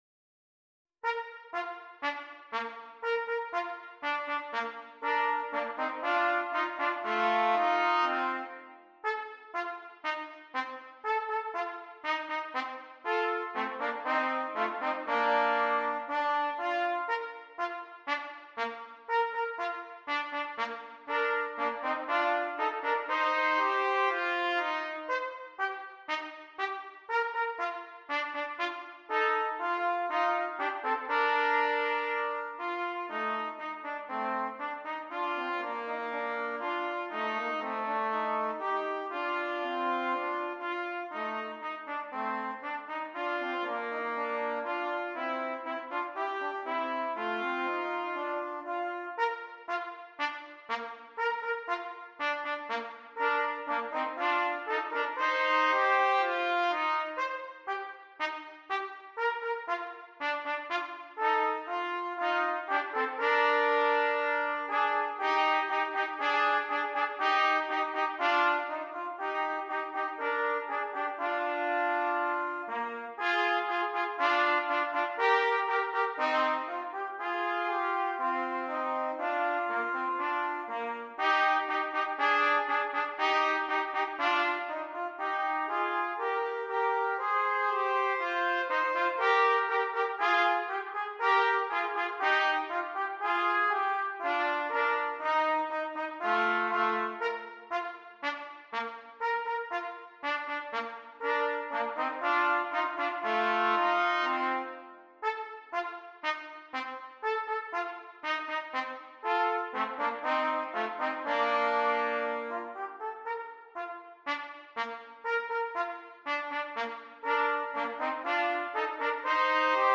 2 Trumpets